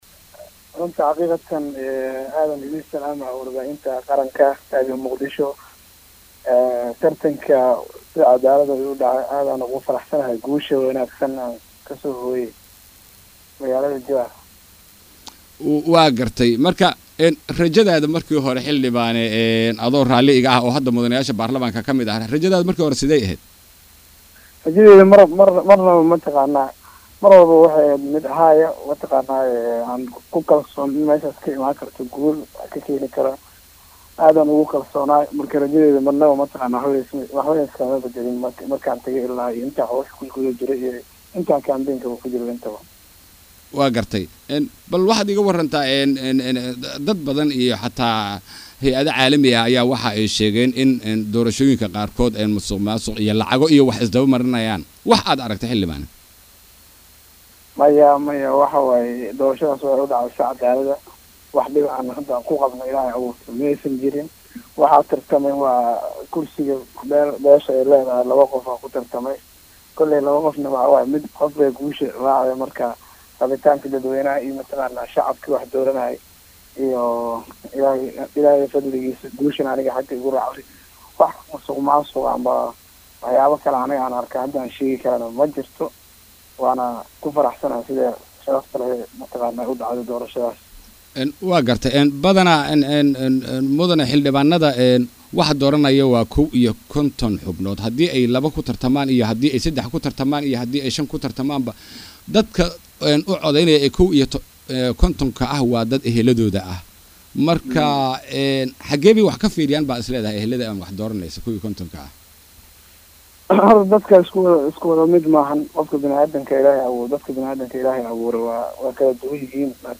Wareysi-Xildhibaan-Eng-Cabdiqaadir-Carabow-Ibrahim.mp3